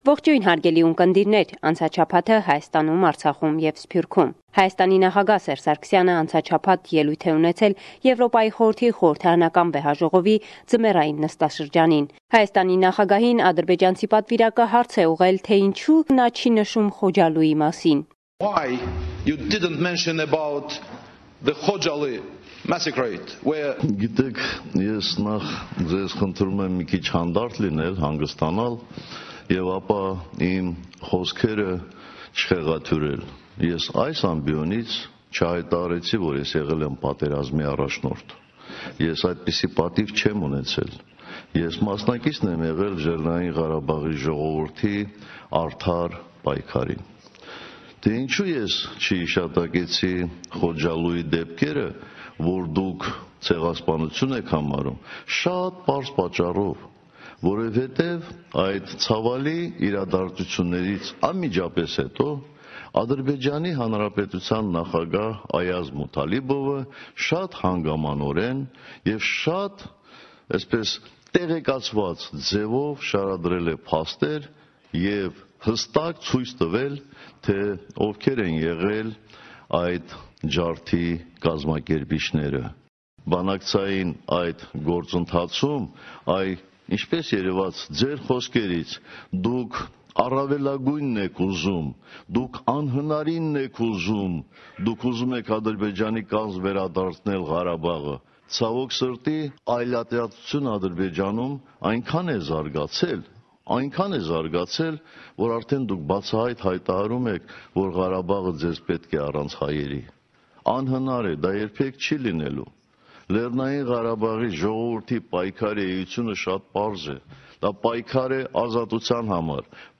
Վերջին Լուրերը – 30 Յունուար, 2018